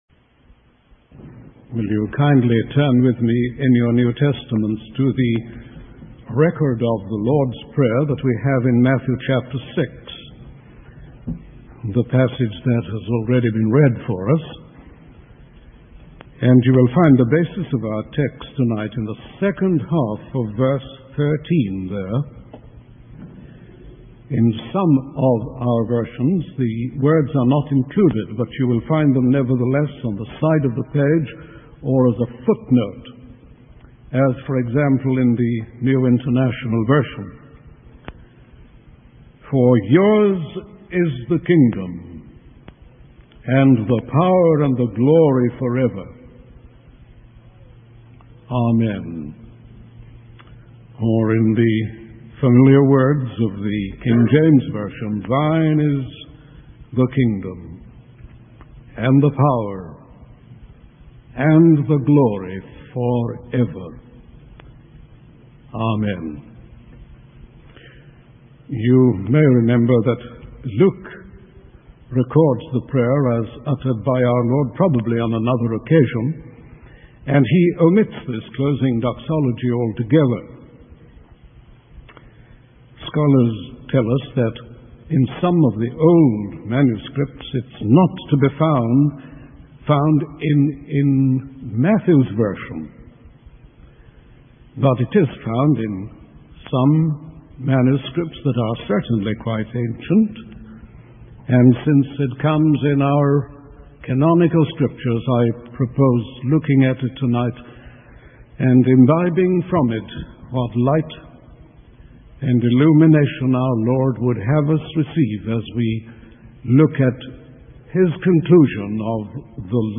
In this sermon, the preacher discusses the importance of praying for various aspects of God's kingdom. He emphasizes the need to hallow God's name, pray for His kingdom to come, and for His will to be done on earth. The preacher also highlights the significance of praying for daily provisions, forgiveness of sins, and protection from temptation and evil.